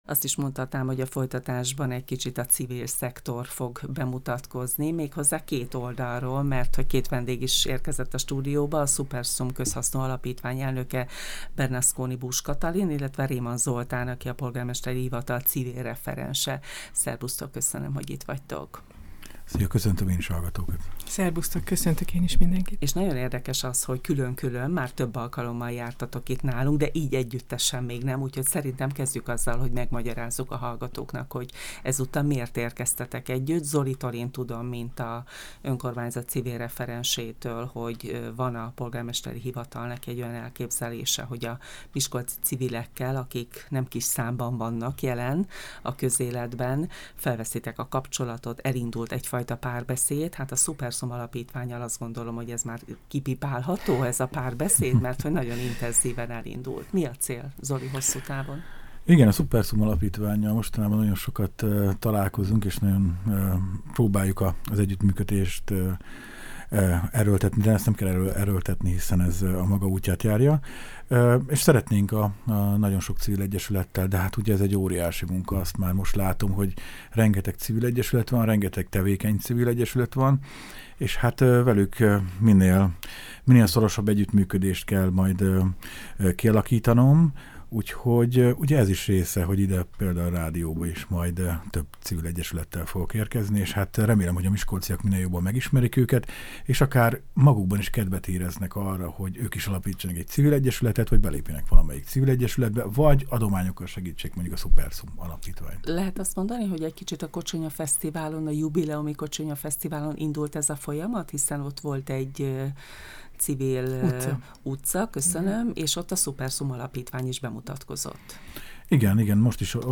Ugrás a tartalomra Egészség- és sportrádió Miskolc - FM 103 Navigáció átkapcsolása A rádió Munkatársaink Műsoraink Podcastok Kapcsolat VII.